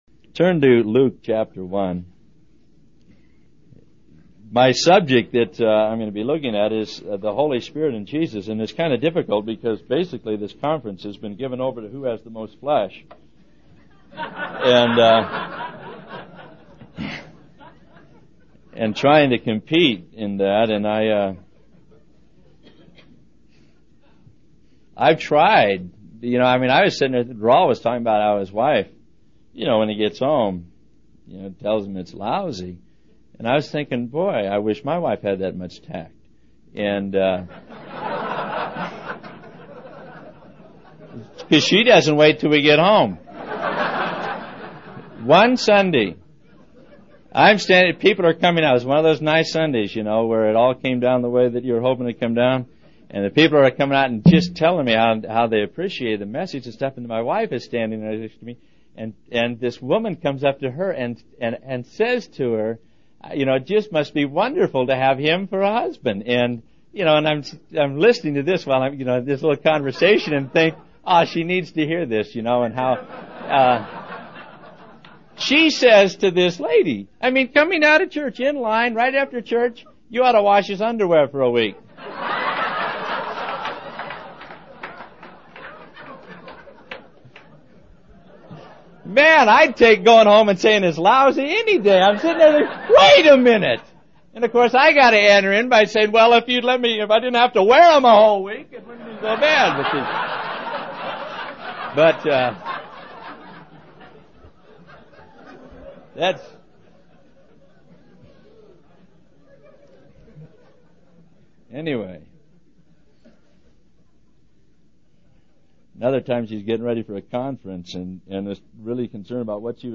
In this sermon, the speaker emphasizes the importance of serving God by serving others. He warns against complaining about our service to God, as it is ultimately service to man that God has called us to.